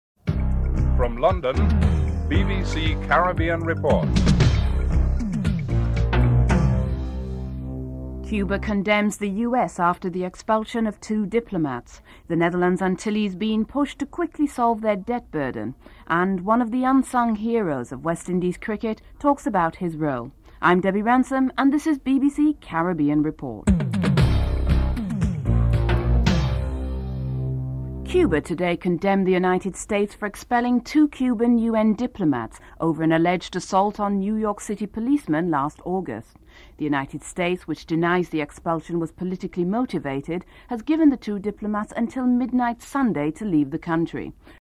5. Report on whether there is a resumption of the exodus of disaffected Haitians (06:53-09:21)